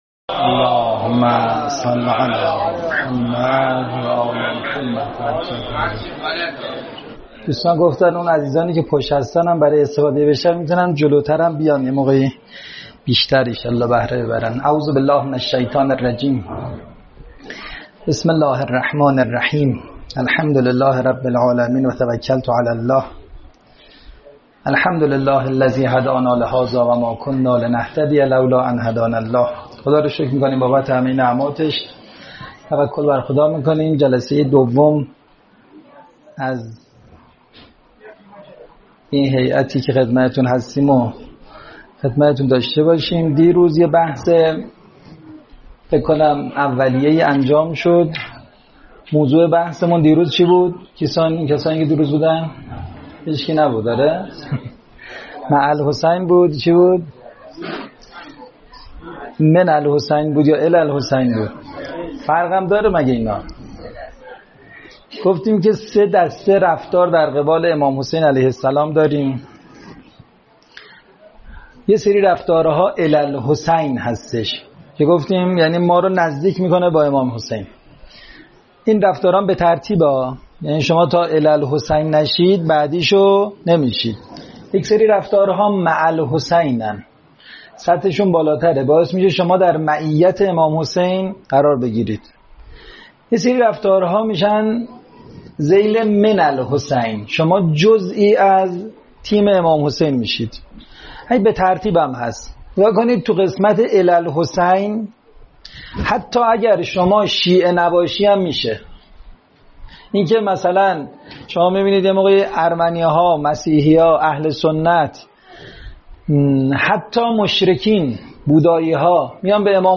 سخنرانی های
سخنرانی